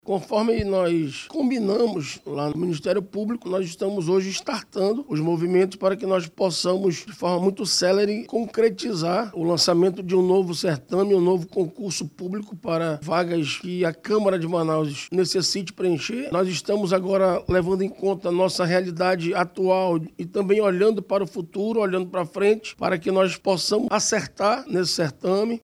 De acordo com o presidente da CMM, o vereador Davi Reis, o levantamento é parte das etapas preparatórias para a elaboração do novo certame.
SONORA-2-LEVANTAMENTO-CARGOS-CMM-.mp3